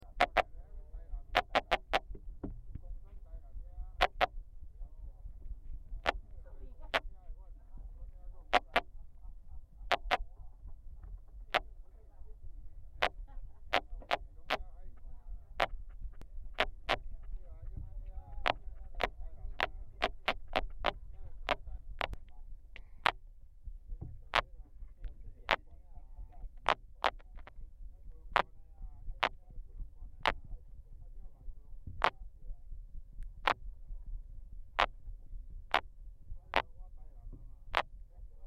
小牙鰏 Gazza minuta
高雄市 鼓山區 高雄港北防波堤起點處
海堤邊保麗龍箱中